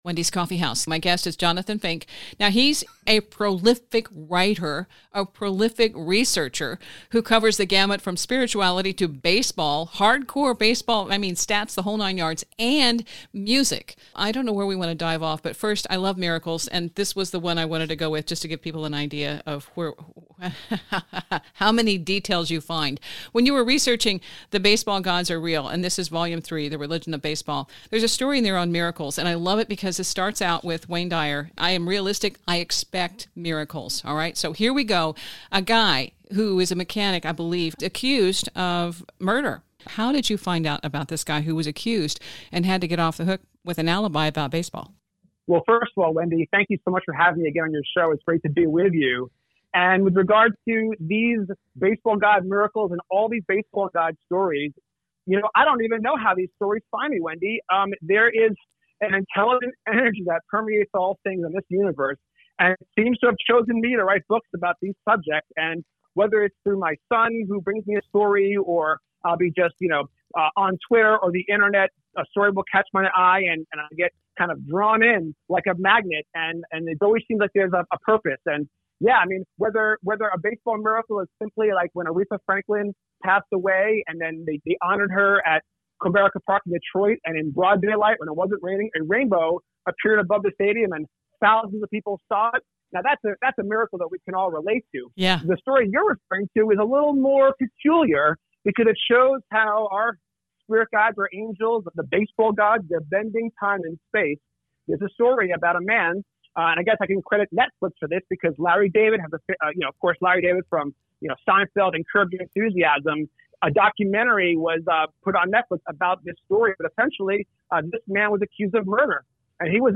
Beyond the paranormal and the metaphysics we highlighted in this interview, the real story is Synchronicity - a play by play .